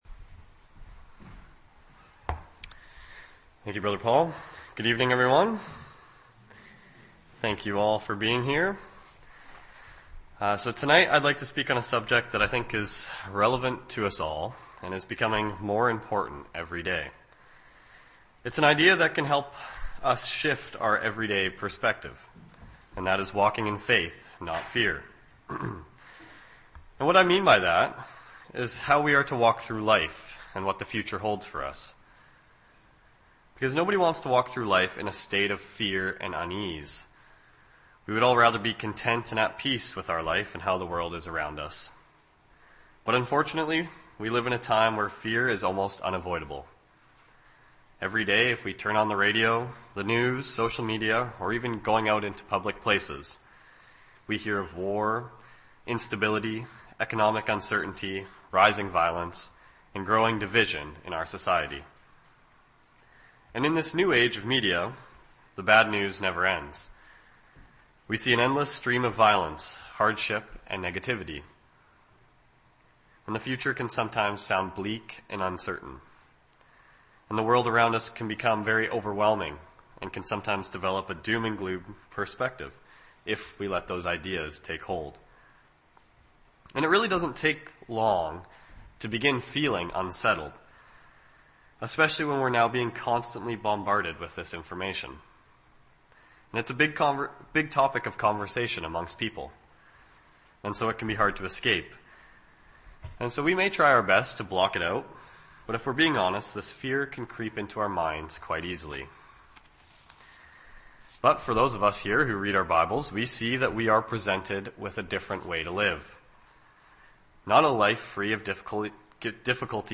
Public Talks